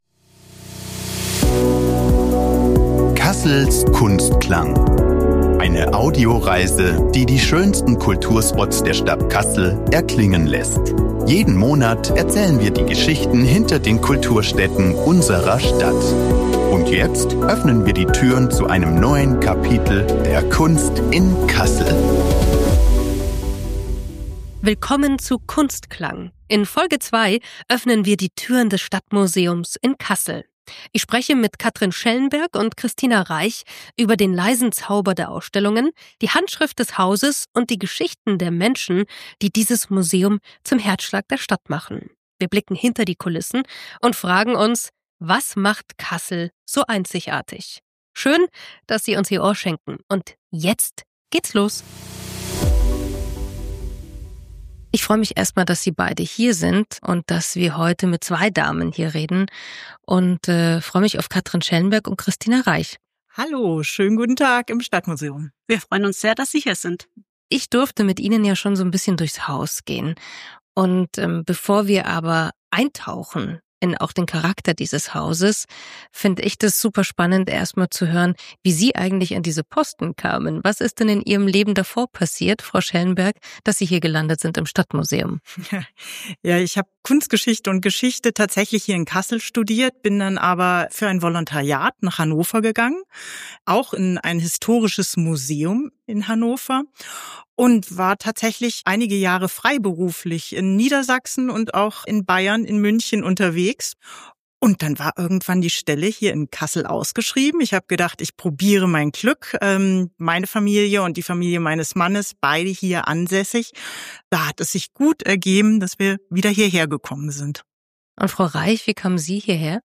In unserer zweiten Folge besuchen wir das Stadtmuseum.